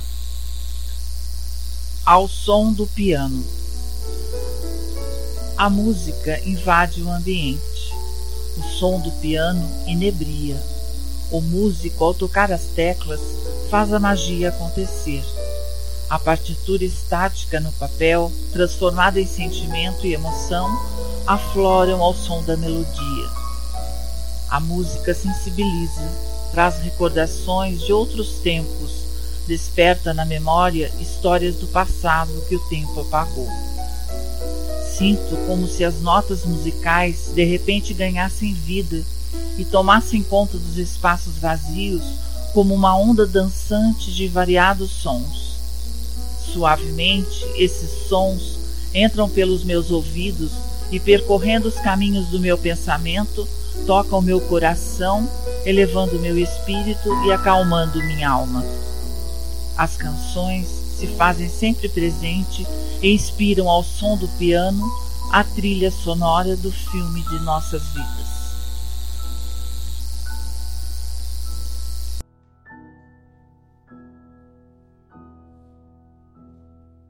Ao+som+do+piano+beethoven_op101.mp3